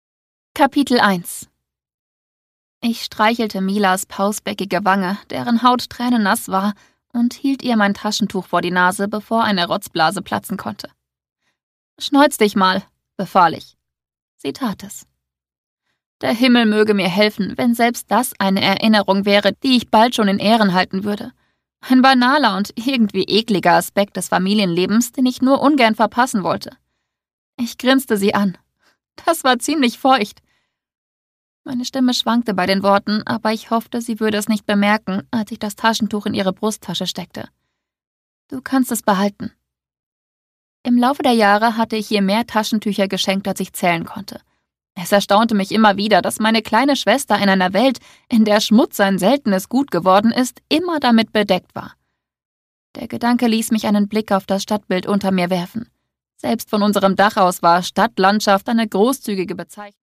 Produkttyp: Hörspiel-Download